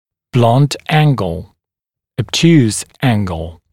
[blʌnt ‘æŋgl] [əb’tjuːs ‘æŋgl][блант ‘энгл] [эб’тйу:с ‘энгл]тупой угол